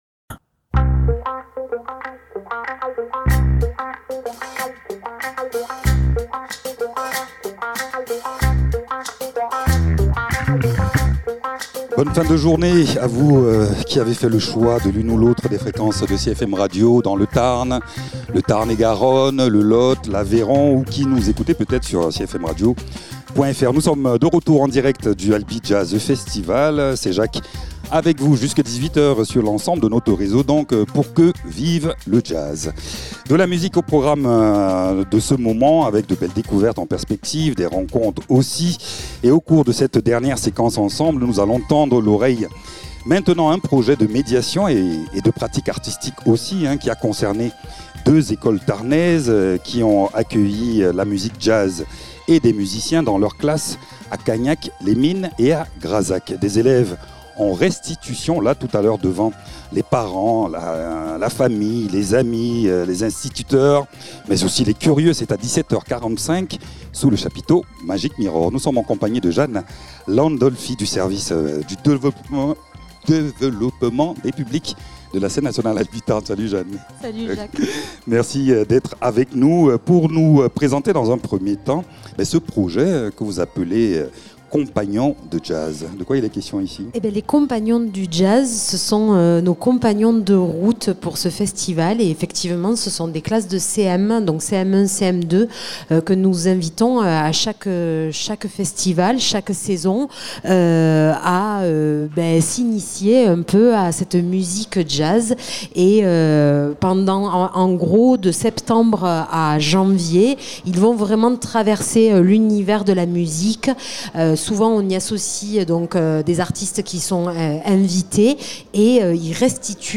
Interviews
Albi Jazz Festival